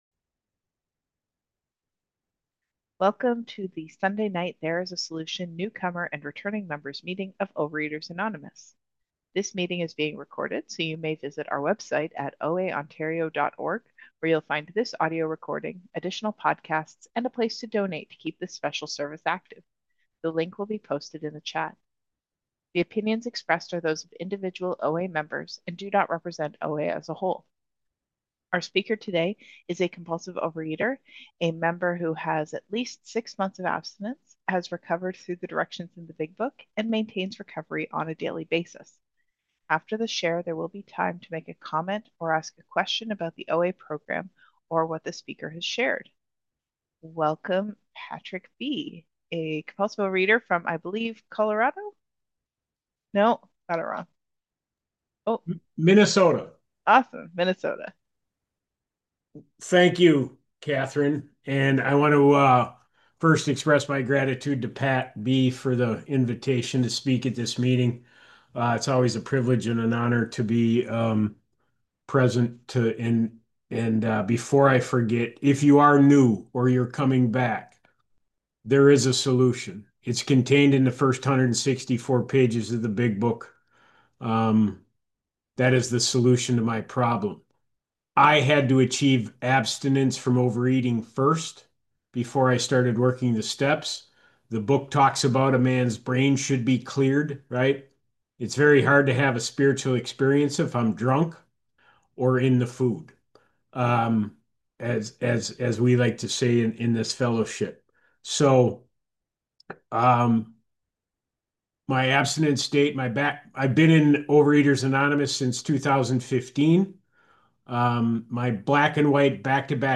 OA Newcomer Meeting